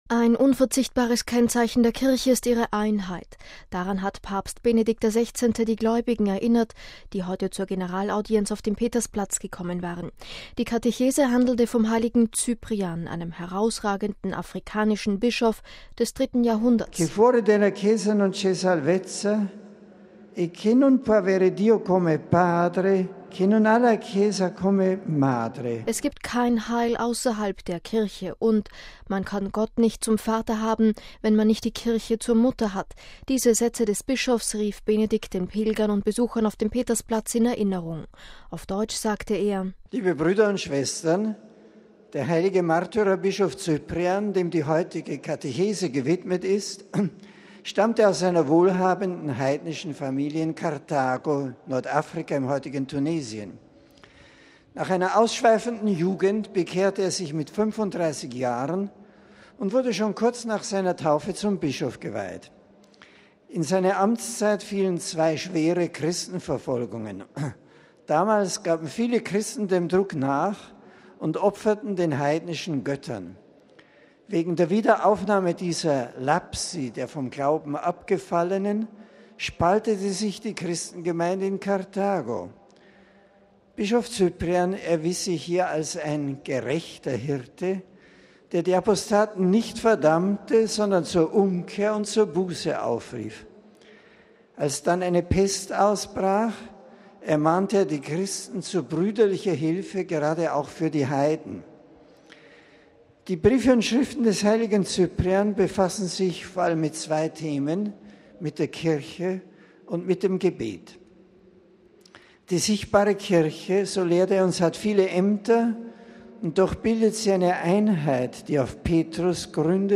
Generalaudienz: „Einheit ist Zeichen der Kirche“
MP3 Ein unverzichtbares Kennzeichen der Kirche ist ihre Einheit: Daran hat Papst Benedikt XVI. die Gläubigen erinnert, die zur Generalaudienz auf dem Petersplatz gekommen waren. Die Katechese handelte heute vom heiligen Cyprian, einem herausragenden afrikanischen Bischof des 3. Jahrhunderts.